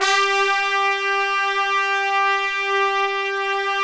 BRS TPTS 0CL.wav